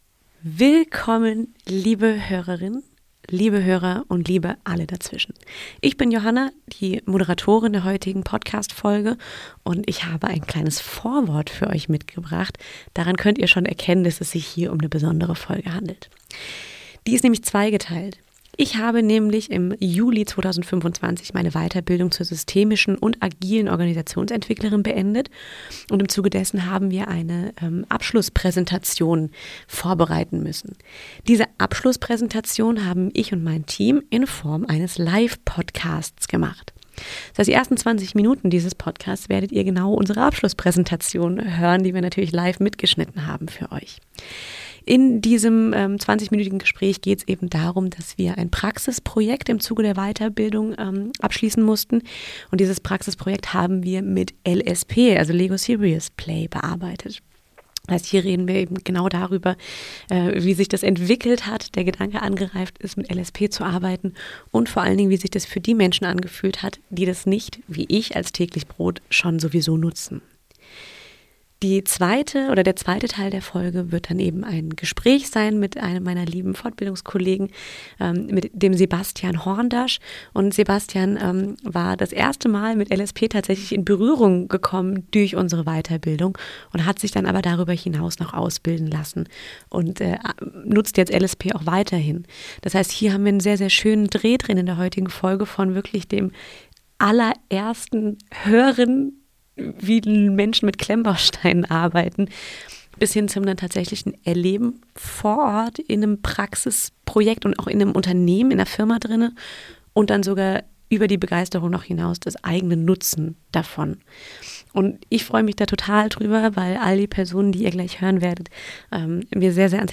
Ein ehrliches Praxisgespräch darüber, wie Organisationen komplexe Themen greifbar machen können.